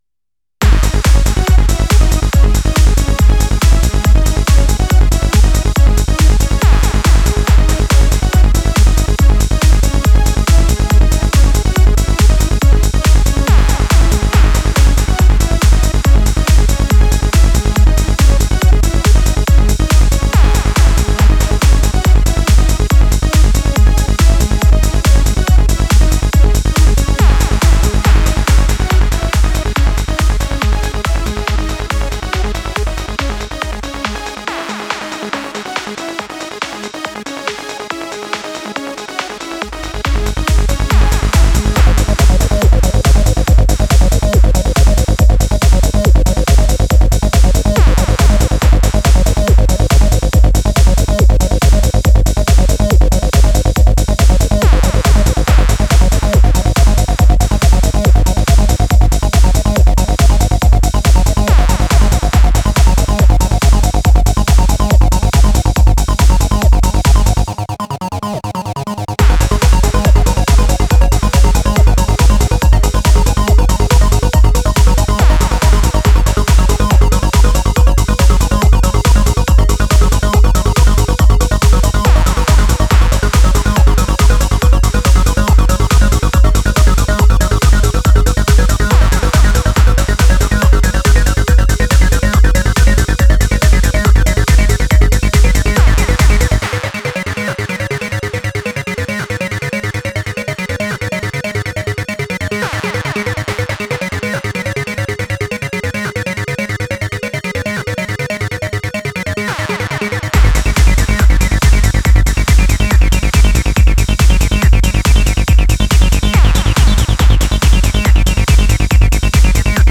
AN IMPRESSIVE COLLECTION OF PSYTRANCE SOUNDS